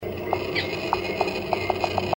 Carpintero Bataraz Chico (Veniliornis mixtus)
Estaba muy metido en el monte, lo grabe mientras taladraba, intente hacerle unas fotos pero fue imposible..
Condición: Silvestre